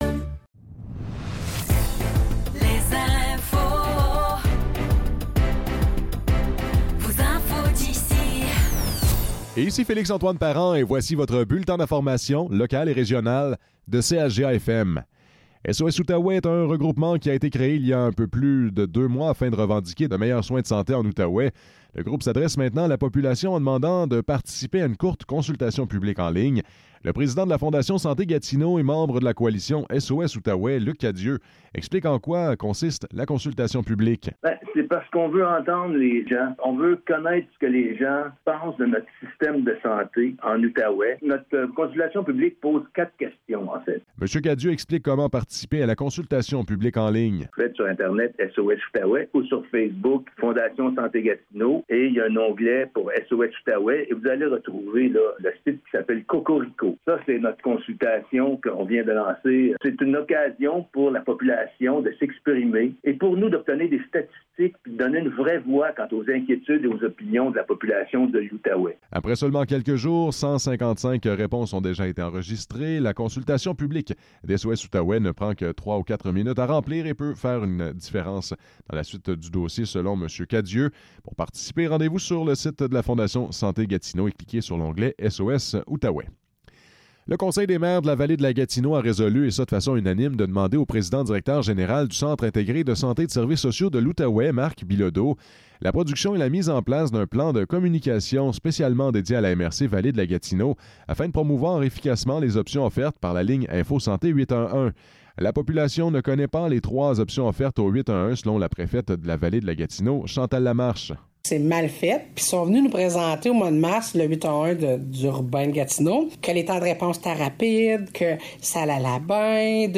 Nouvelles locales - 24 juillet 2024 - 10 h